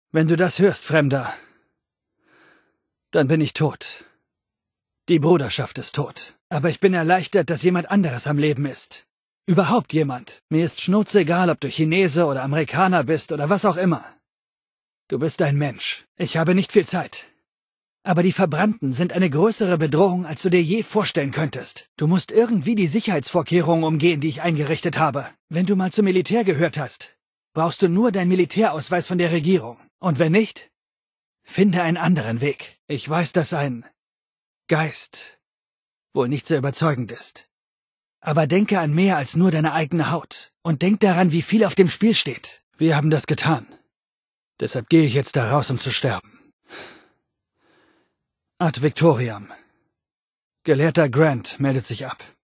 Fallout 76: Audio-Holobänder